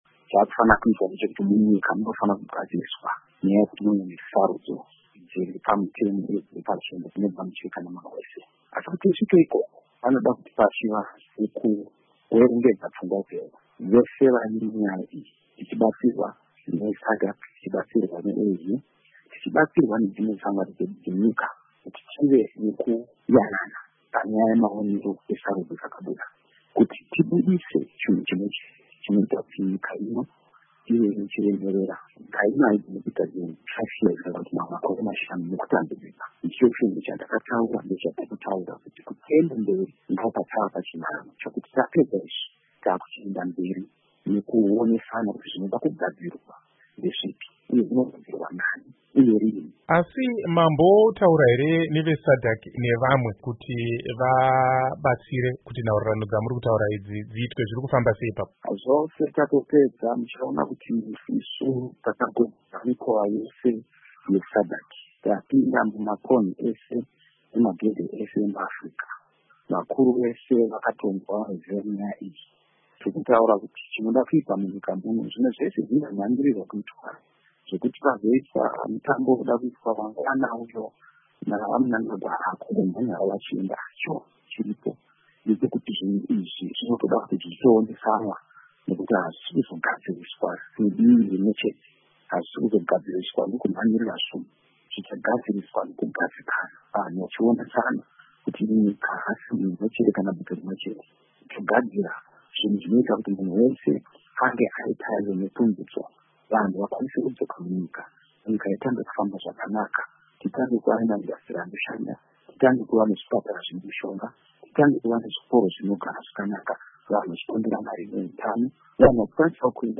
Hurukuro naVaNelson Chamisa